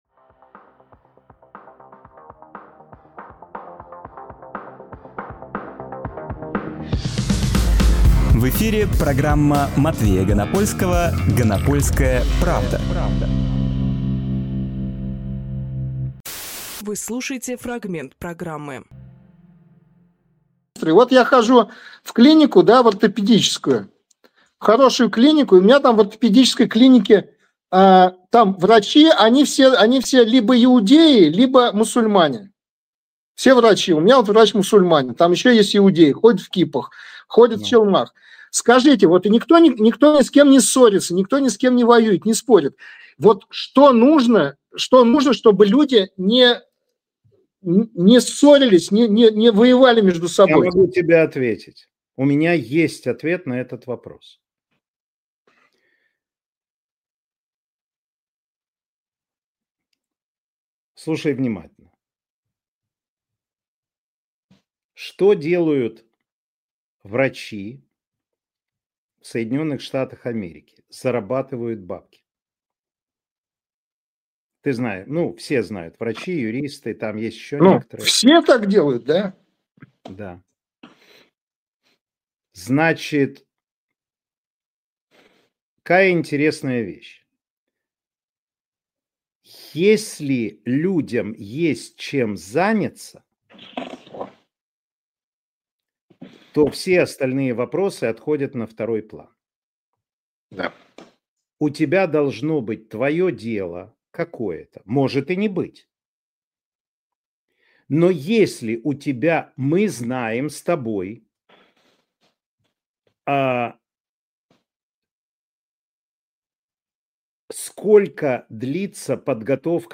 Фрагмент эфира от 28.06.25